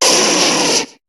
Cri d'Arcanin dans Pokémon HOME.